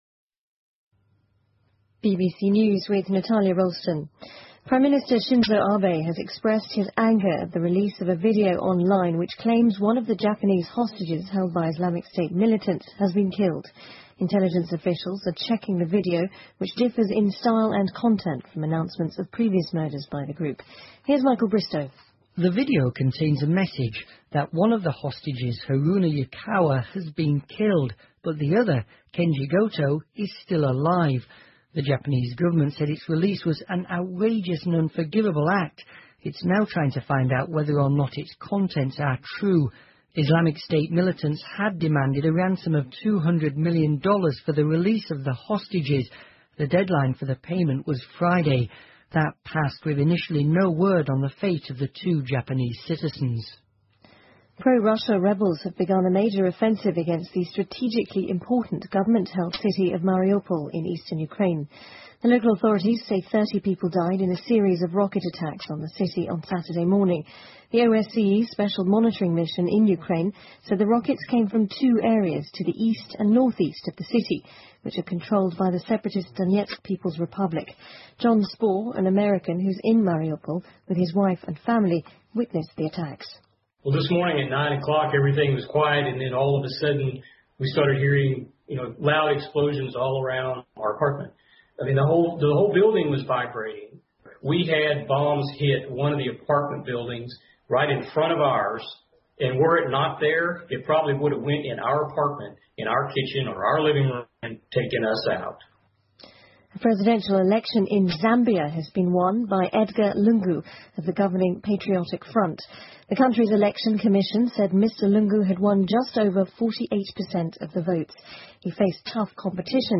英国新闻听力 日本人质被IS武装组织杀害 听力文件下载—在线英语听力室